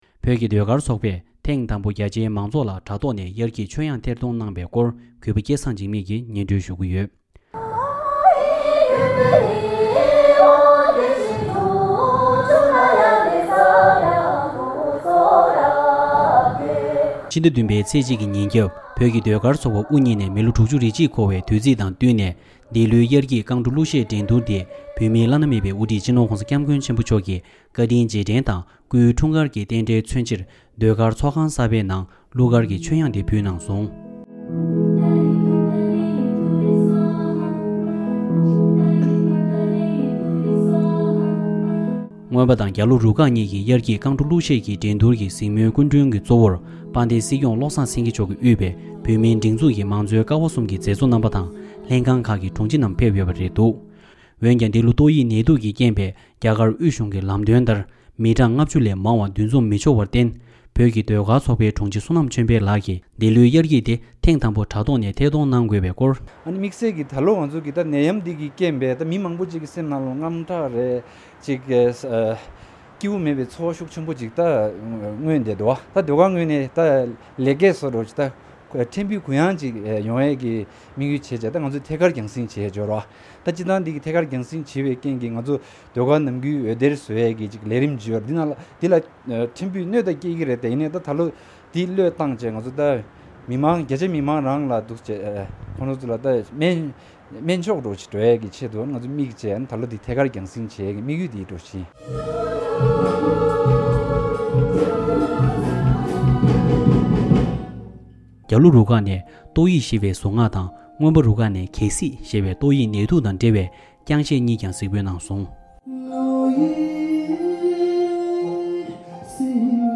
༄༅།། ཕྱི་ཟླ་༧་པའི་ཚེས་༡་ཉིན་ནི་བོད་ཀྱི་ཟློས་སྒར་ཚོགས་པ་དབུ་བརྙེས་ནས་ལོ་༦༡་འཁོར་བའི་དུས་ཆེན་ཡིན་ཞིང་། ལོ་འདིའི་ཟློས་གར་ཚོགས་པའི་རྔོན་པ་དང་རྒྱལ་ལུའི་རུ་ཁག་ཟུང་གི་དབྱར་སྐྱིད་ཀྱི་རྐང་བྲོ་དང་གླུ་གཞས་འགྲན་སྡུར་རྣམས་བོད་ཀྱི་བླ་ན་མེད་པའི་དབུ་ཁྲིད་སྤྱི་ནོར་༧གོང་ས་སྐྱབས་མགོན་ཆེན་པོ་མཆོག་གི་བཀའ་དྲིན་རྗེས་དྲན་དང་སྐུའི་འཁྲུངས་སྐར་གྱི་རྟེན་འབྲེལ་མཚོན་ཕྱིར་ཟློས་སྒར་ཚོགས་ཁང་གསར་པའི་ནང་དུ་གླུ་གར་ཀྱི་མཆོད་དབྱངས་ཕུལ་ཡོད་པའི་སྐོར།